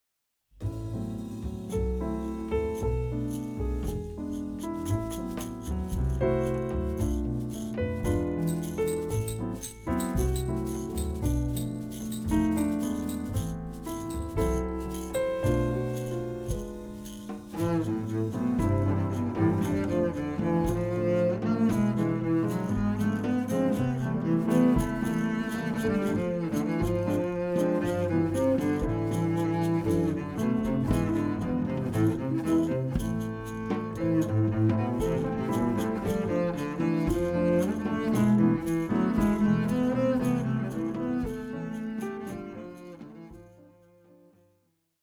bass
piano
drums